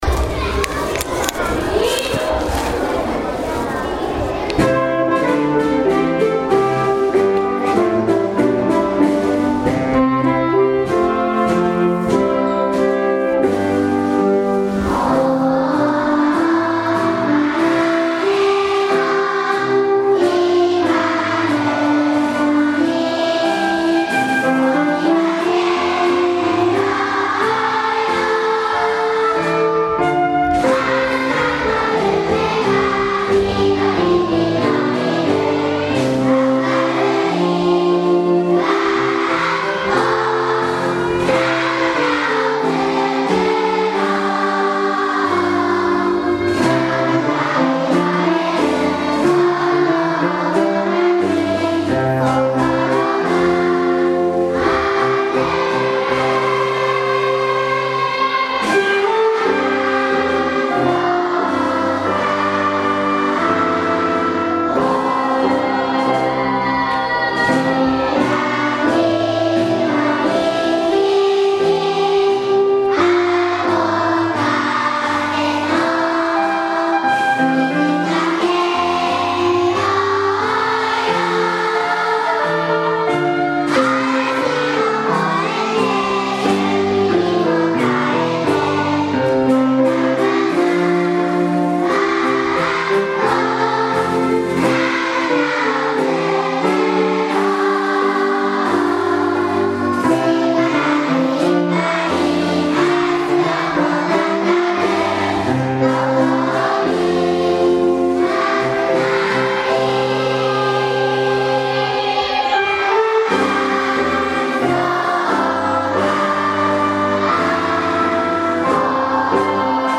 音楽鑑賞会（校歌の音声）
長田小の体育館がコンサートホールに生まれ変わりました。
ソプラノサックス、アルトサックス、ティナーサックス、バリトンサックス、ピアノ、ドラムの６名のメンバーです。
校歌も演奏してくれました。元気いっぱい校歌を歌いました。